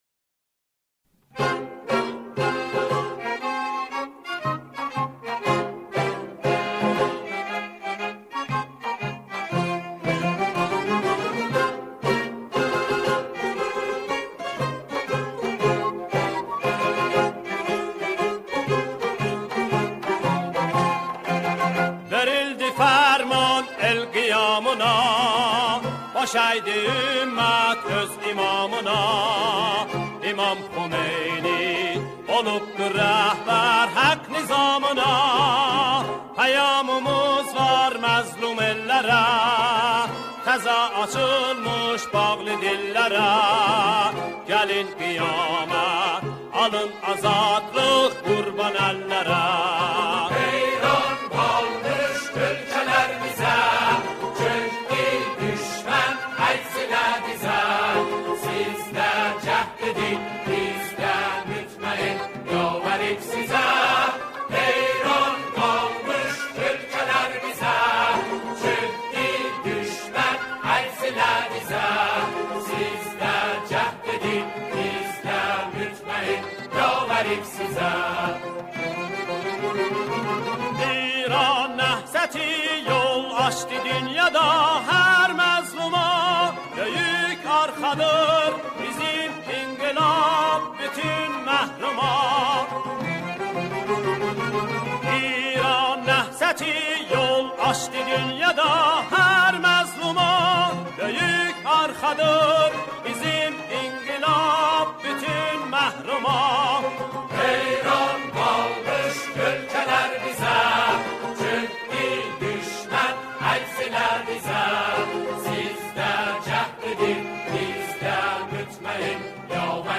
همخوانی شعری آذری